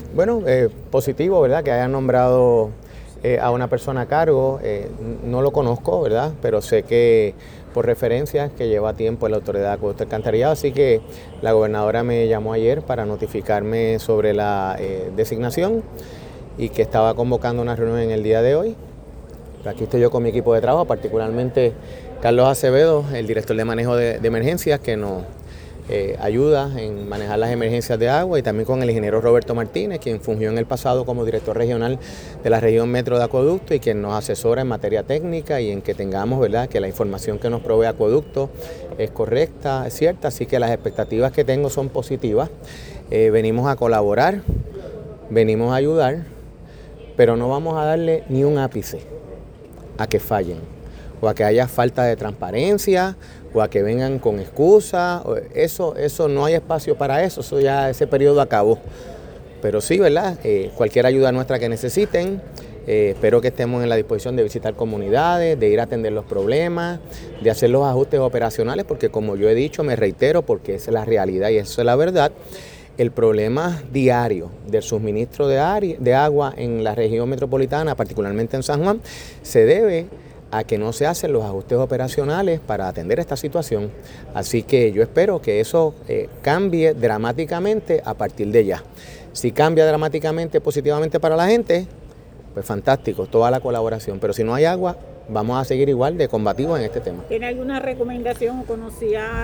Expresiones que se dieron a su llegada a una reunión en La Fortaleza con la gobernadora Jenniffer González, sus homólogos de Bayamón, Guaynabo, Canóvanas, Cataño, Loíza, Trujillo Alto y senadores y representantes de la zona metropolitana para atender directamente la situación del servicio de agua en el área metropolitana junto al presidente de la AAA, Luis González, y la nueva dirección regional de la agencia.